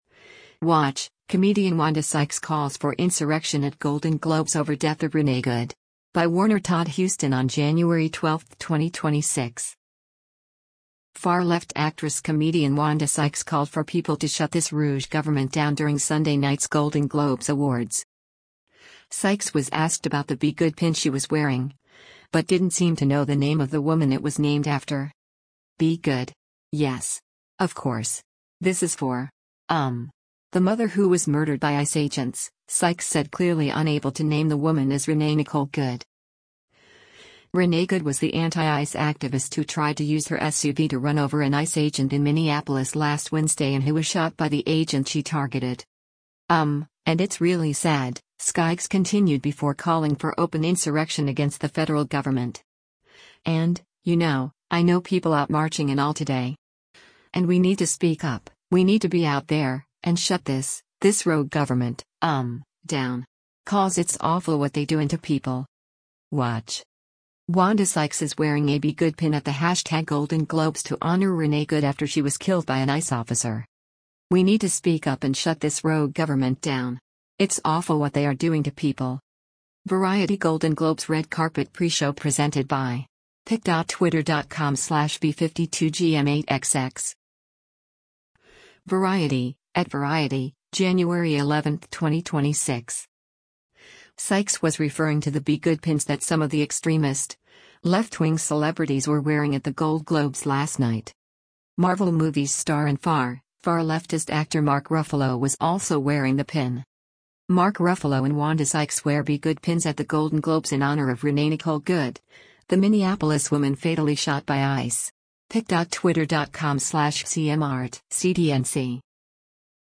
Far-left actress-comedian Wanda Sykes called for people to “shut this rouge government down” during Sunday night’s Golden Globes Awards.